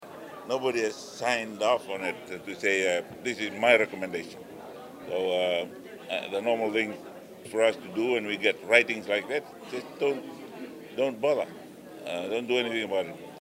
Rabuka’s remarks came during a media interaction in Nadi yesterday, following his participation in the opening ceremony of the Pacific Specialist Healthcare Hospital.